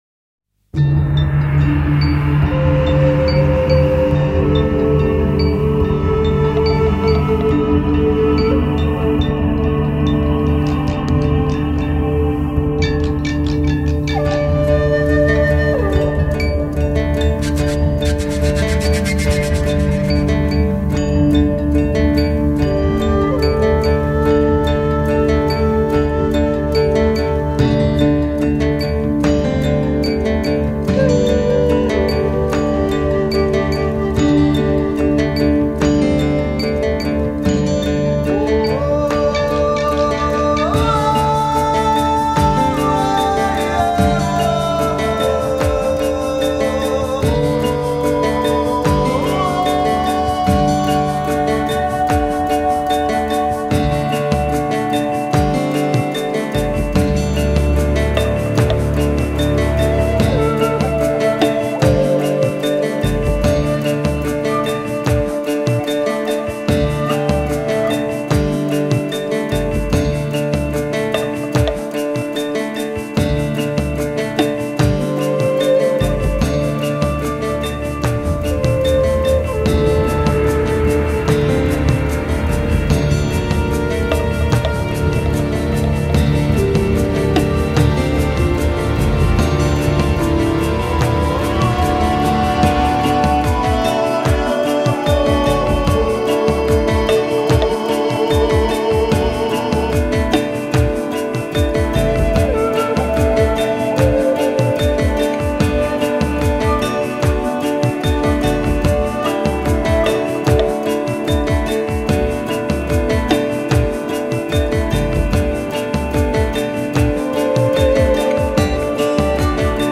ethnique - profondeurs - epique - flute de pan - perou